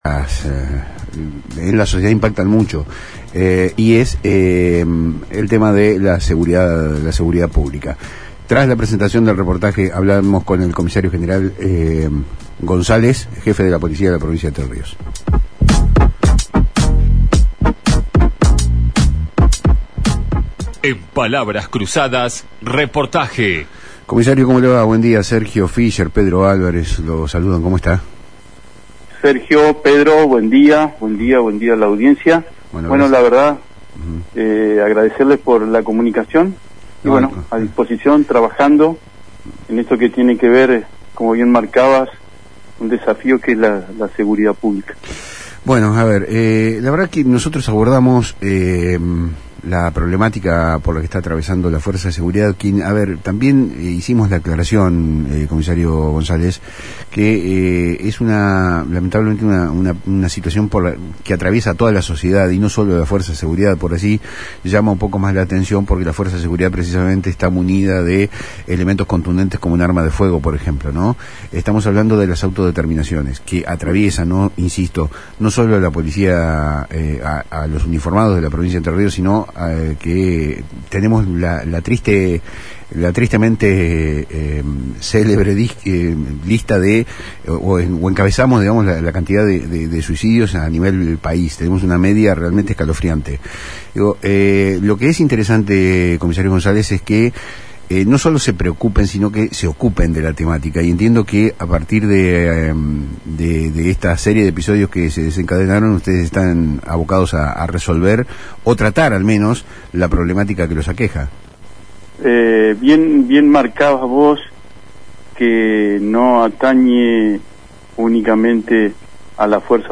En una entrevista exclusiva, el comisario general Claudio González, jefe de la policía de la provincia de Entre Ríos, abordó, en Palabras Cruzadas por FM Litoral, un tema que ha sacudido a la fuerza de seguridad: los preocupantes índices de suicidio.